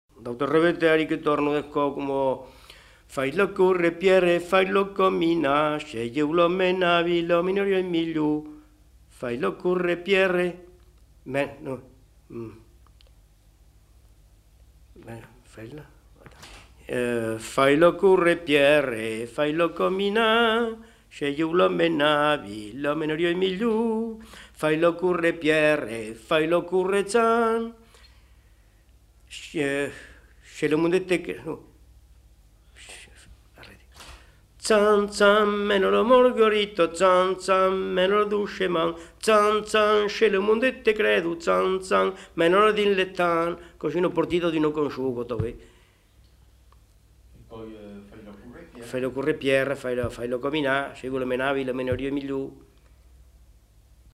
Aire culturelle : Périgord
Lieu : Daglan
Genre : chant
Effectif : 1
Type de voix : voix d'homme
Production du son : chanté
Classification : danses